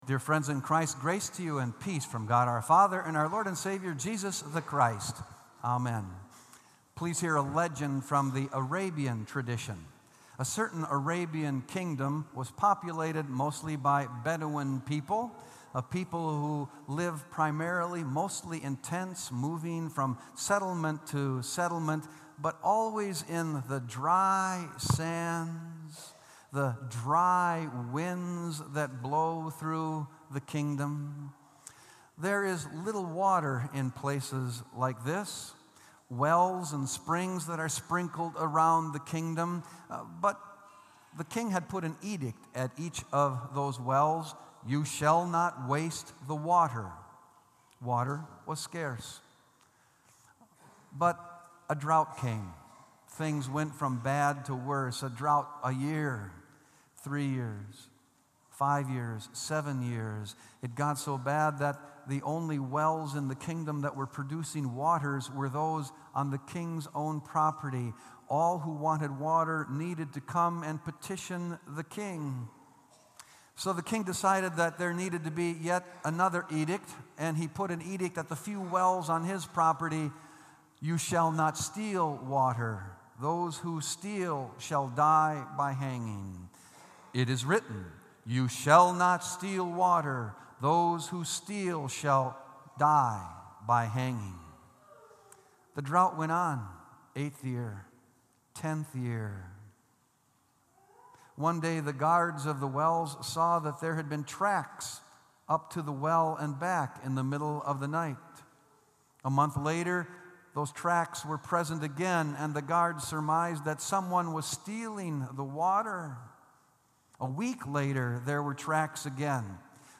Sermon “Justified By Faith” | Bethel Lutheran Church